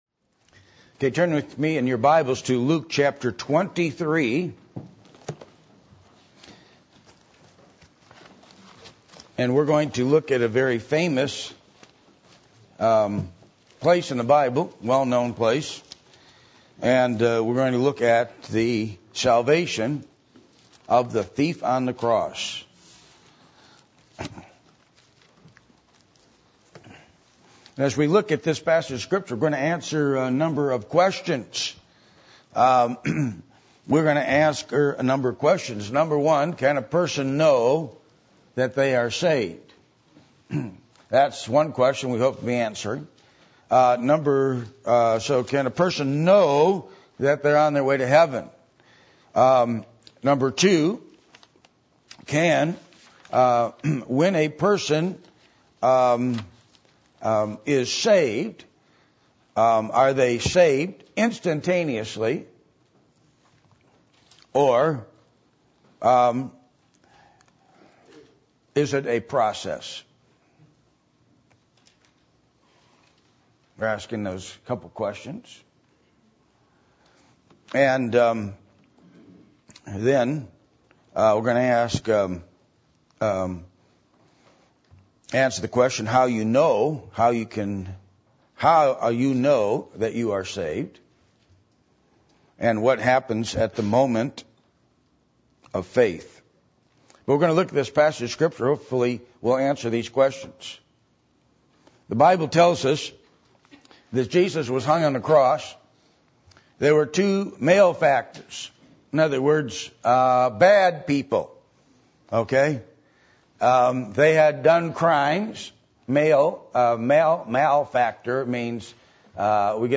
Passage: Luke 23:33-45 Service Type: Sunday Morning %todo_render% « Why Prayers Are Not Answered Which Generation Will You Be?